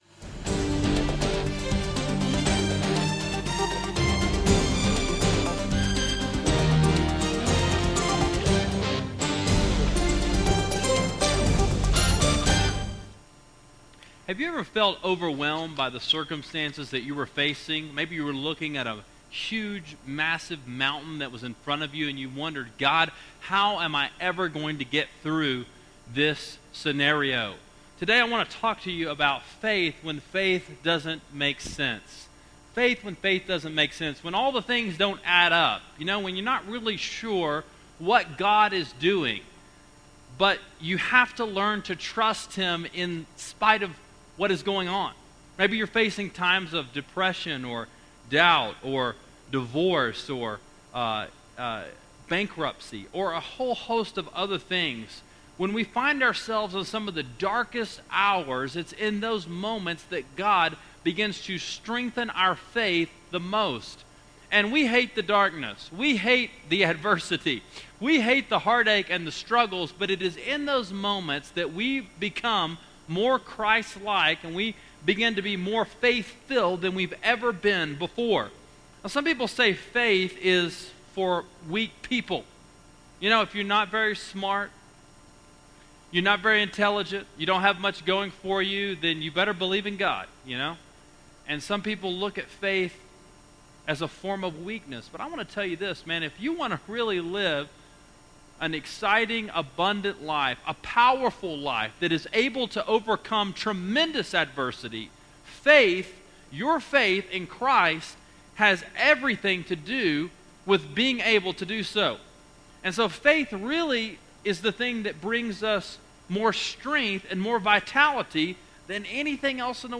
Heroes: Faith When Faith Doesn’t Make Sense – Genesis 22:1-18, Hebrews 11:8-13 – Sermon Sidekick